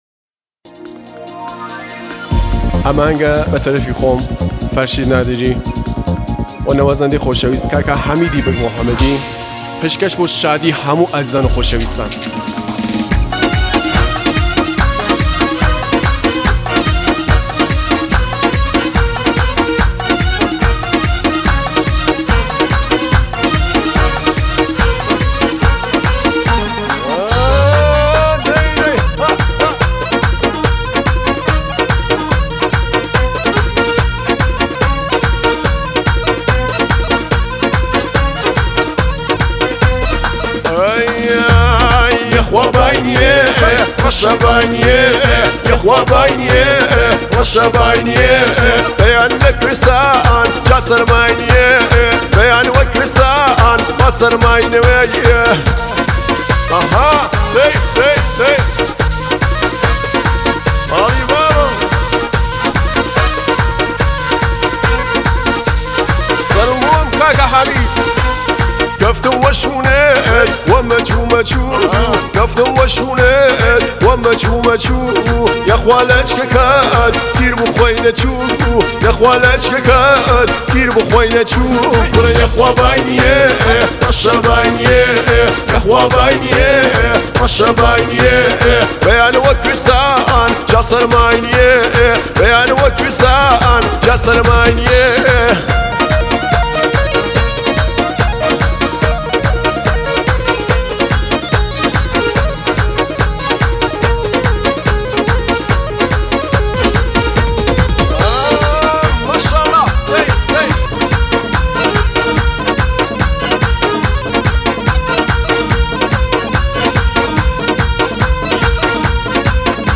آهنگ کردی و سنندجی